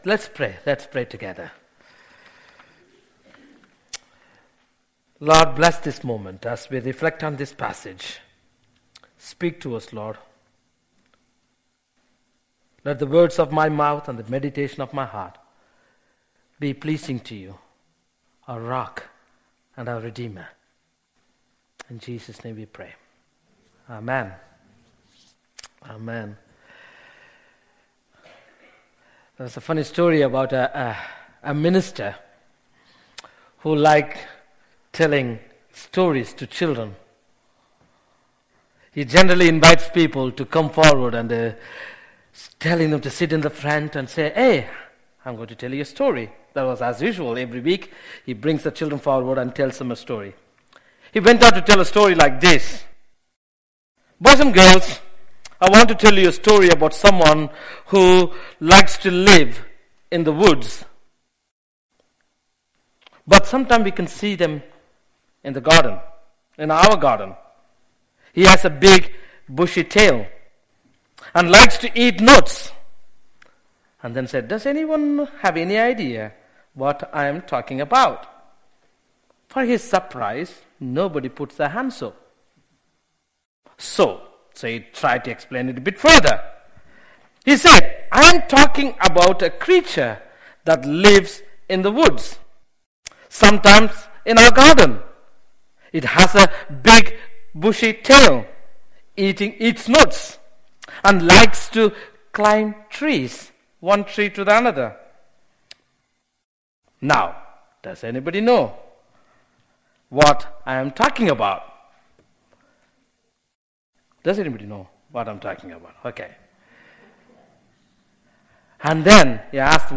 An audio version of the sermon is also available.
01-19-sermon-ordinary-to-extraordinary.mp3